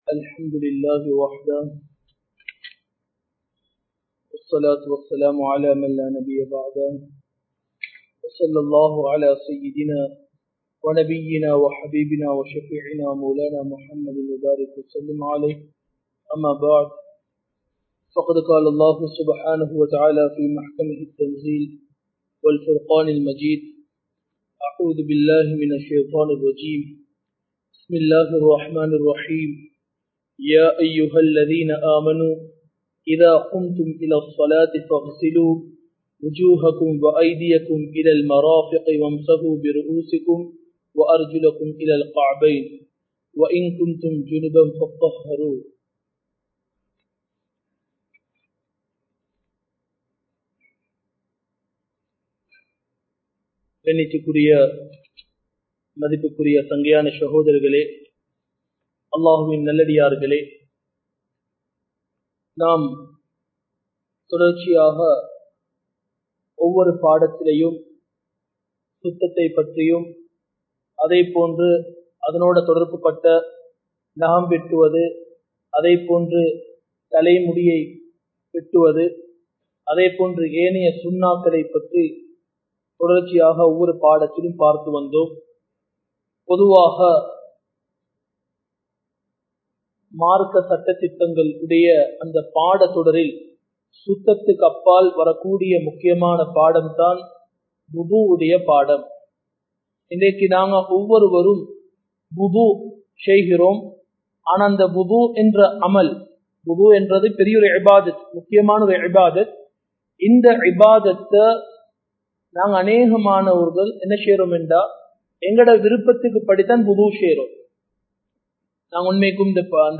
Majma Ul Khairah Jumua Masjith (Nimal Road)